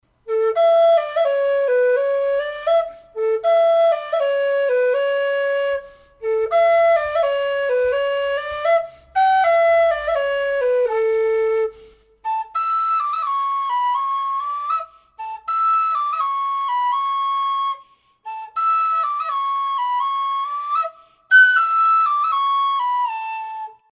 Альт A (пластик)
Альт A (пластик) Тональность: A
Неприхотливый пластиковый альт. Обладает отличным балансом и хорошей отзывчивость.